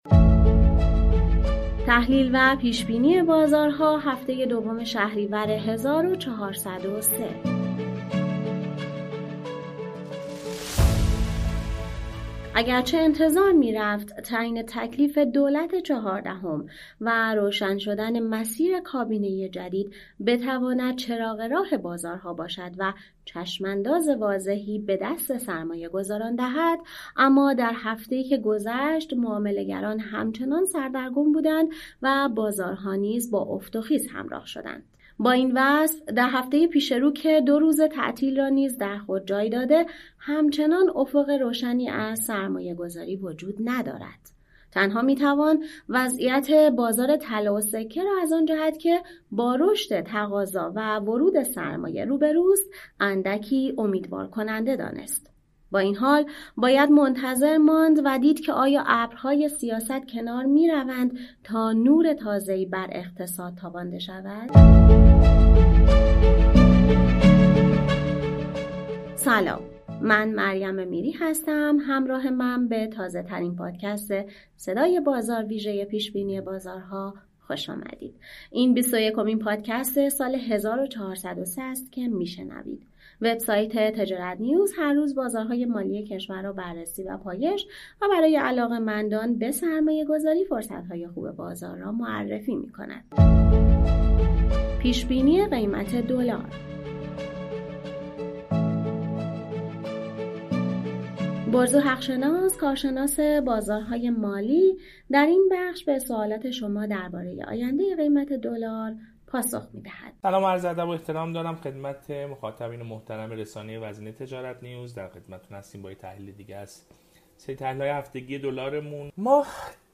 کارشناس بازارهای مالی در گفت‌وگو